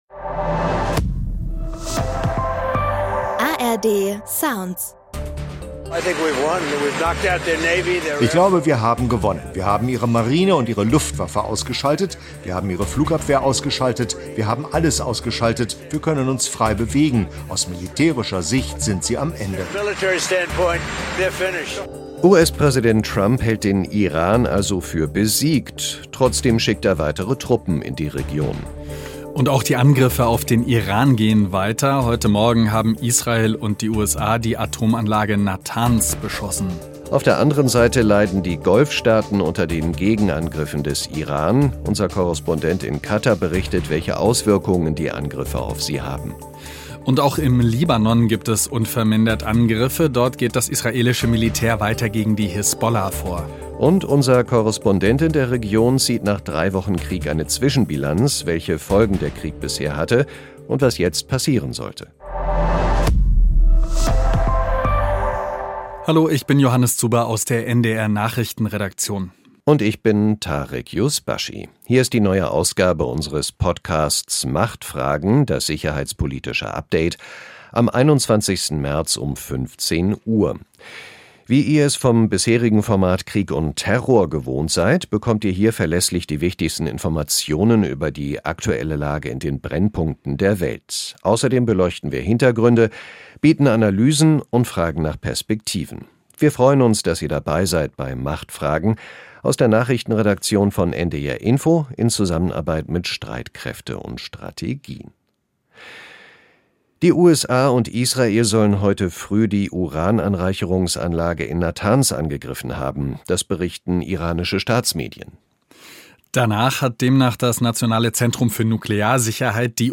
Unser Korrespondent berichtet von den Folgen für die iranischen Nachbarn.